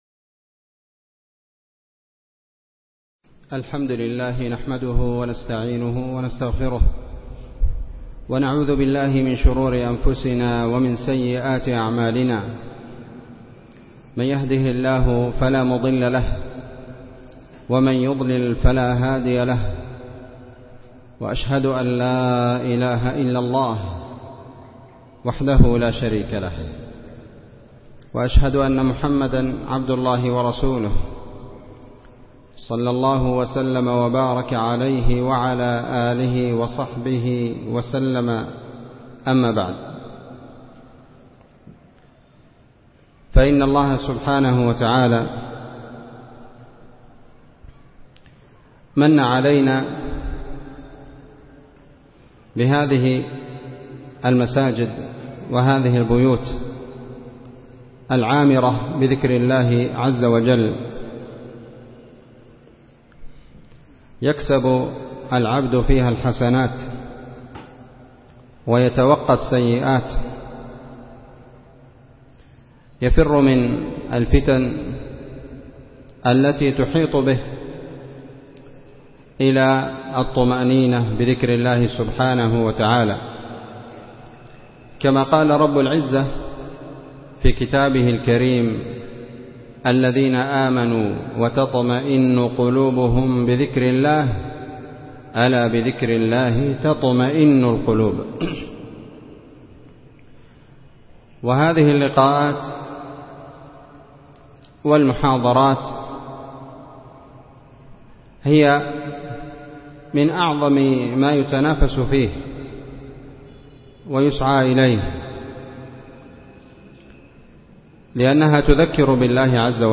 محاضرة
مسجد العيسائي- تعز